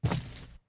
Footstep_Concrete 06.wav